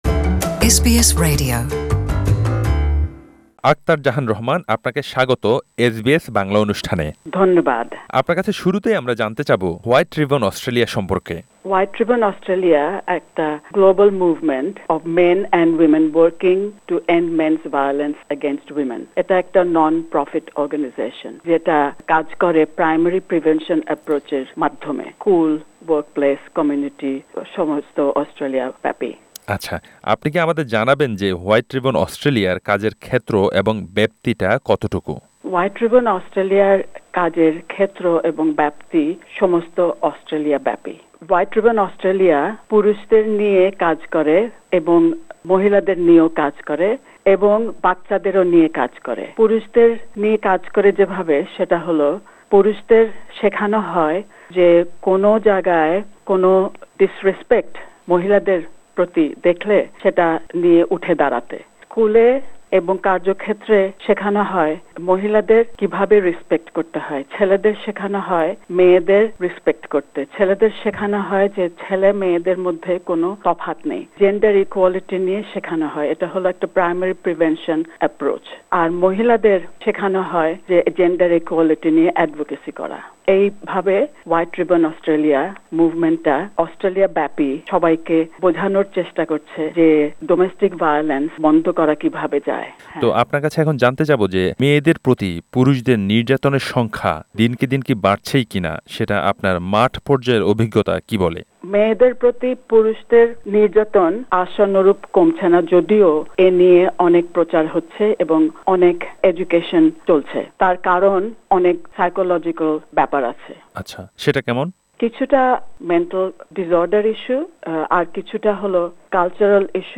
Source: SBS Bangla Click the above audio link to listen to the interview in Bangla.